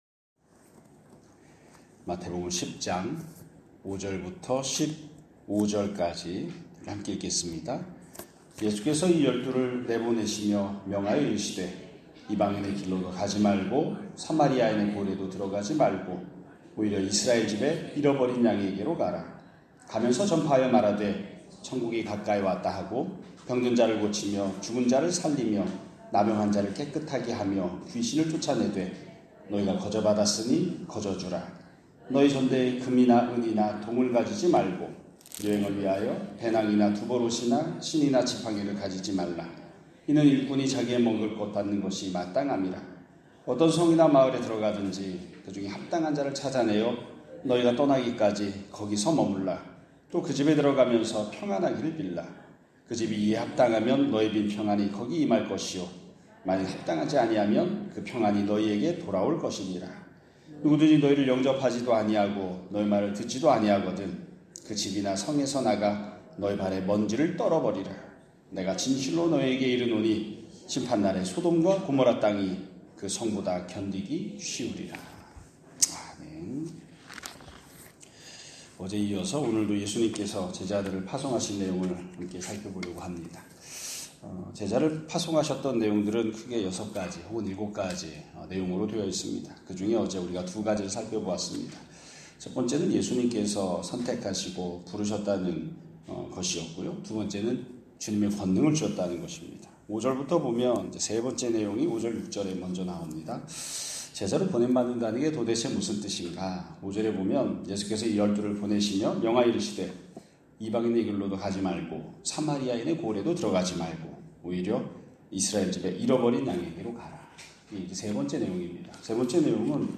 2025년 8월 6일 (수요일) <아침예배> 설교입니다.